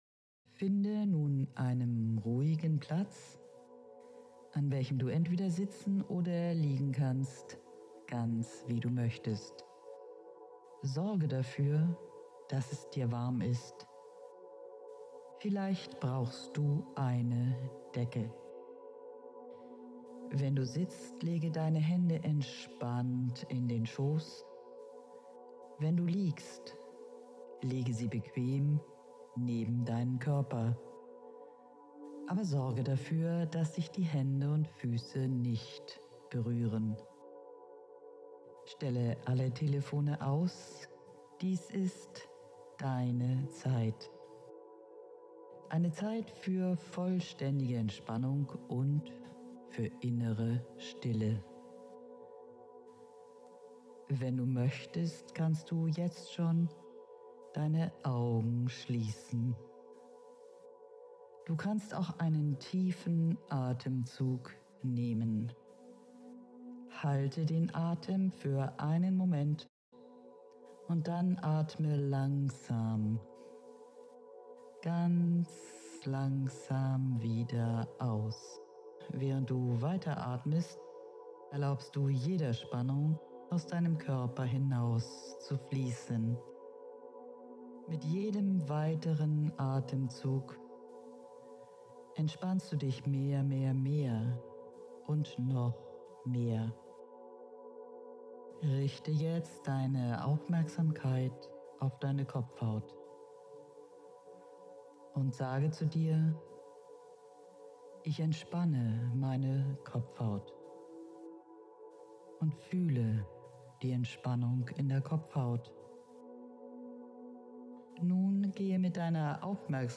Abnehmen mit Hypnose!
Die Meditation zum Buch „Das Essverhalten ändern!“.